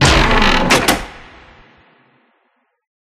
Chest1.ogg